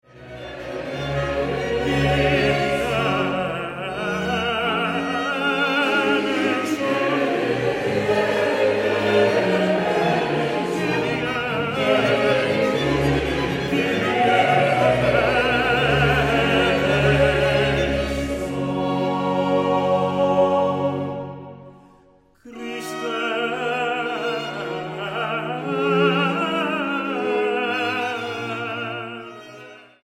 a cinco voces y tenor solista
Música Barroca Mexicana